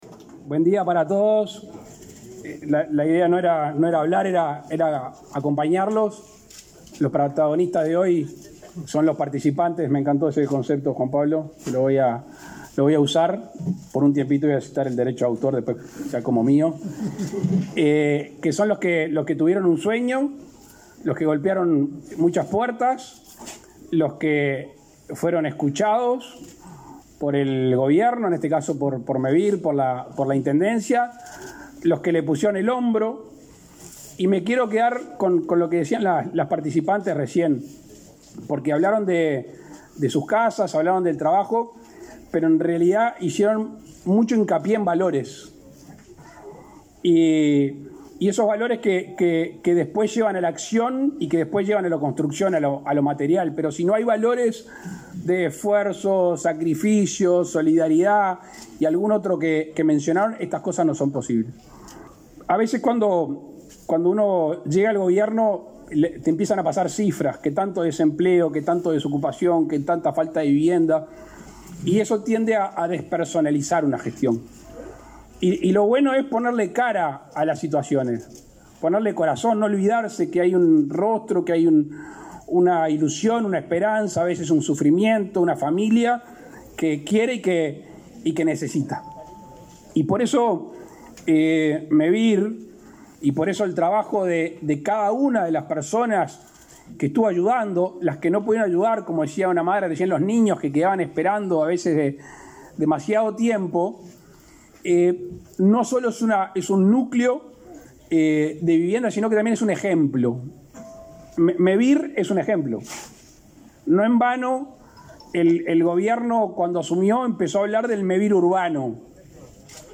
Palabras de Lacalle Pou en inauguración de viviendas en Rafael Perazza